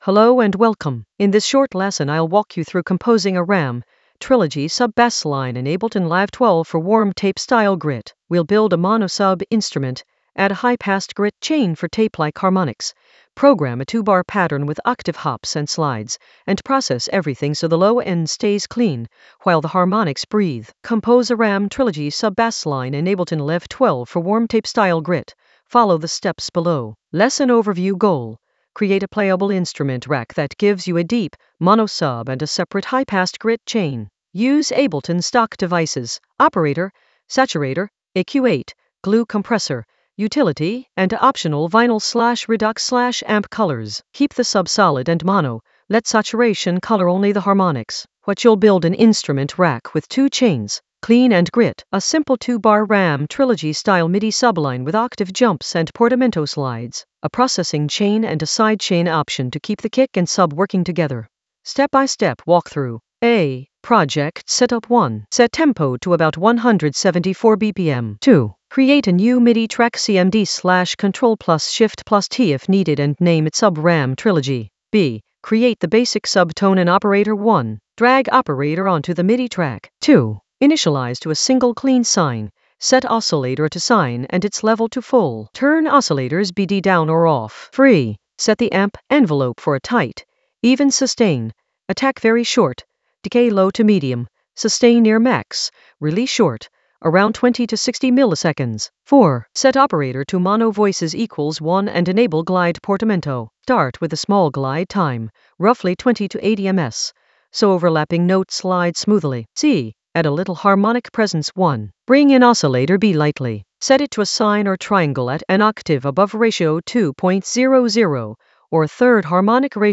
An AI-generated beginner Ableton lesson focused on Compose a Ram Trilogy sub bassline in Ableton Live 12 for warm tape-style grit in the Basslines area of drum and bass production.
Narrated lesson audio
The voice track includes the tutorial plus extra teacher commentary.